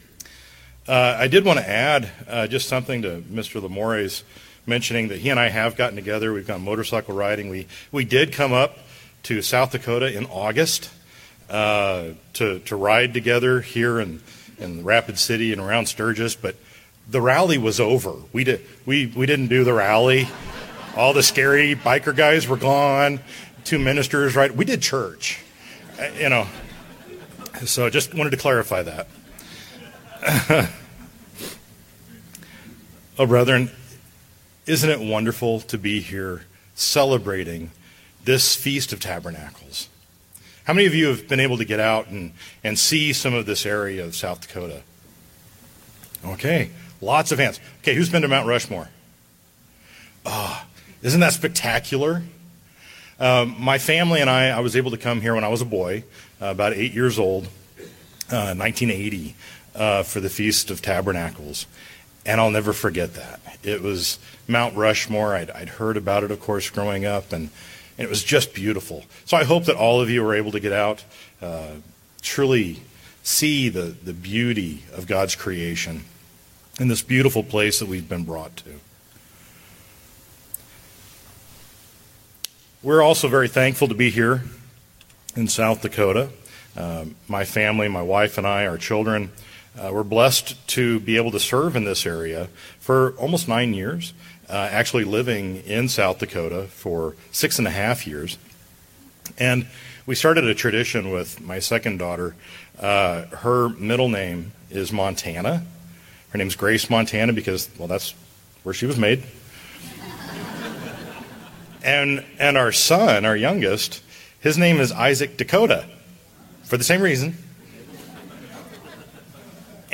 This Family Day sermon looks at how Family is what God's plan of salvation and Holy Days are all about. Building the family of God begins with seeing each other as Christ does.
Given in Rapid City, South Dakota